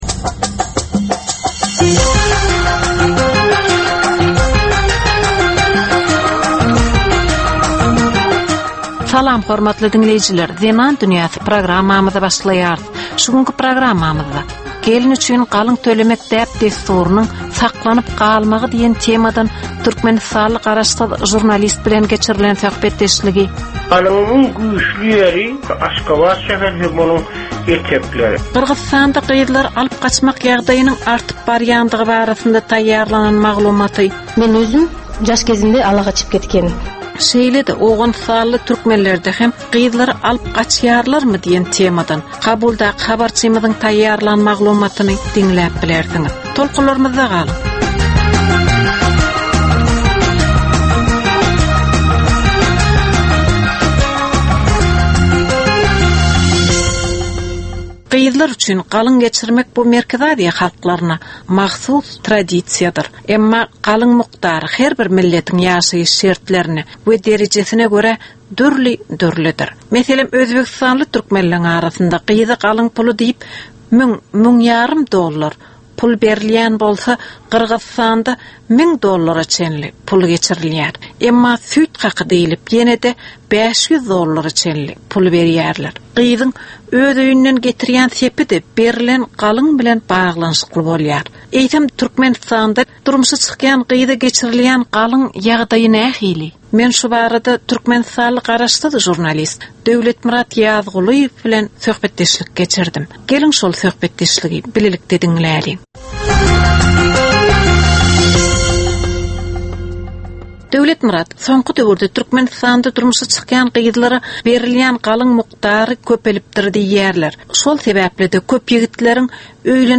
Türkmen we halkara aýal-gyzlarynyň durmuşyna degişli derwaýys meselelere we täzeliklere bagyşlanylyp taýýarlanylýan 15 minutlyk ýörite gepleşik. Bu gepleşikde aýal-gyzlaryn durmuşyna degişli maglumatlar, synlar, bu meseleler boýunça synçylaryň we bilermenleriň pikirleri, teklipleri we diskussiýalary berilýär.